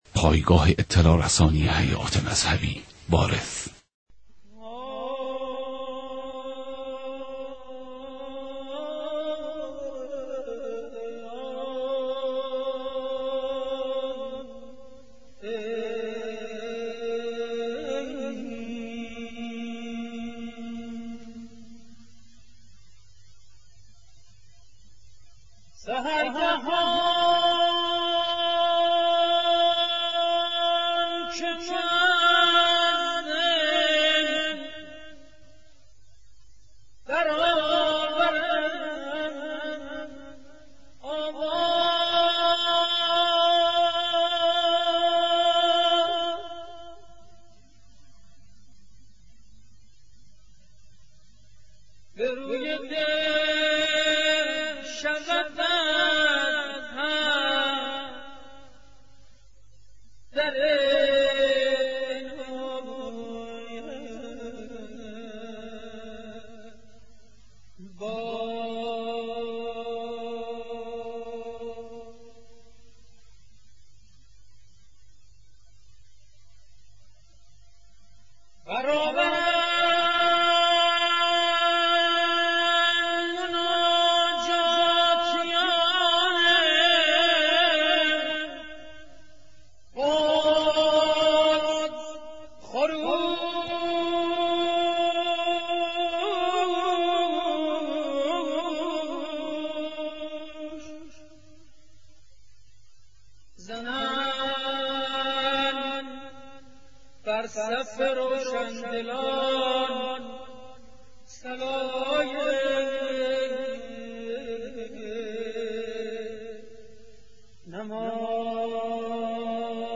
مناجات با امام رضا(ع)...